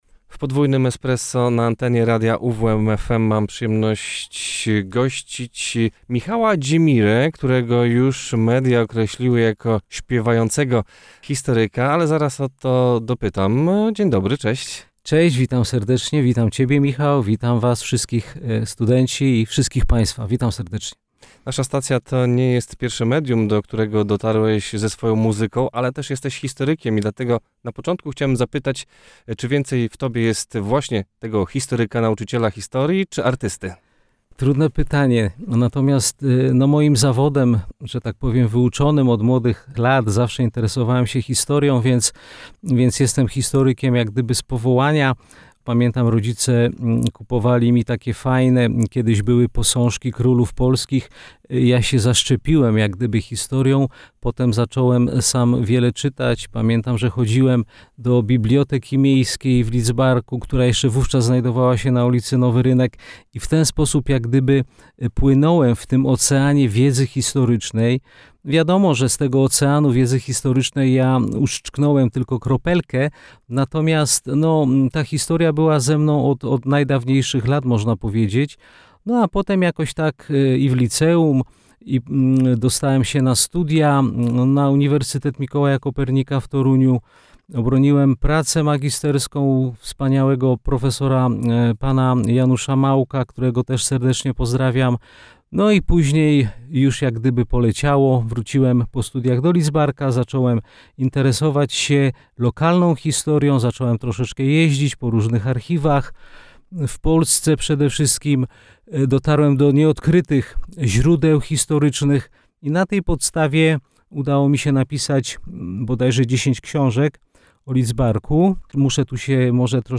w rozmowie na naszej antenie przybliżył losy Lidzbarka nad Welem od samego początku do czasów po drugiej wojnie światowej.